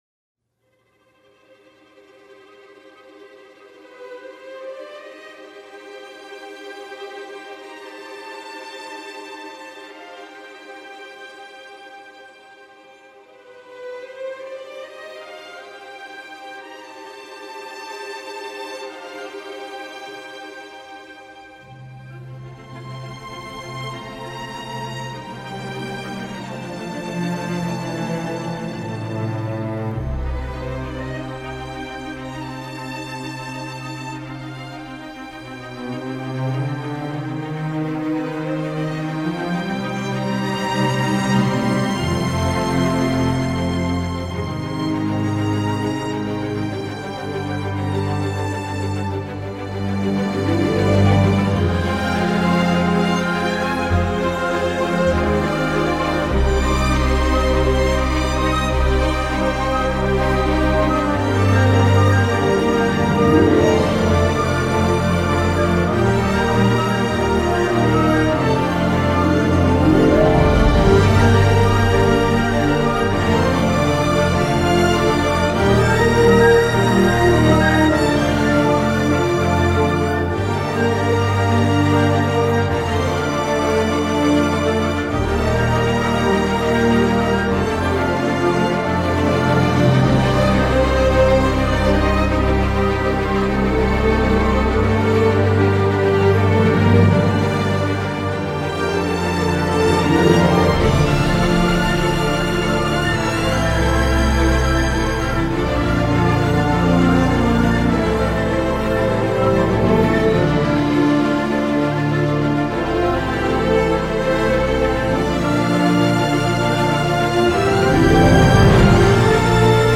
pour orchestre d’abord sombre, tendue et introvertie